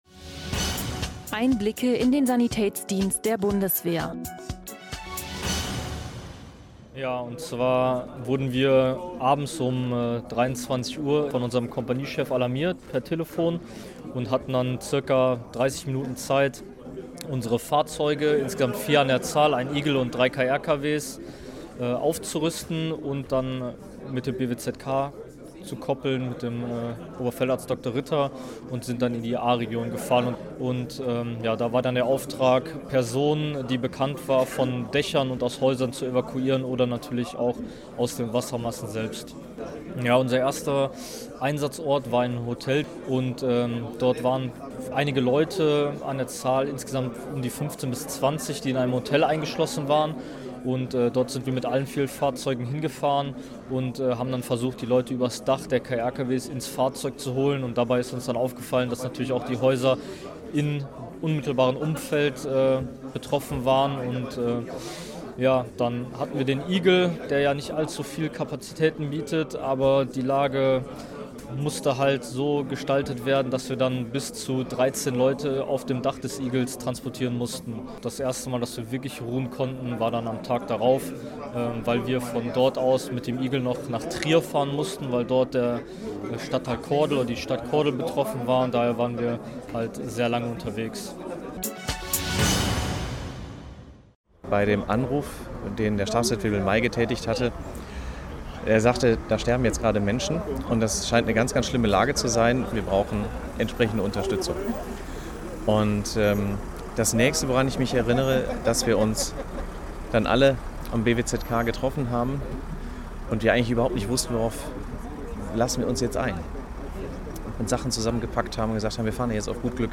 Interview: Helfer der Ahrtalflut
interview-helfer-der-ahrtalflut-data.mp3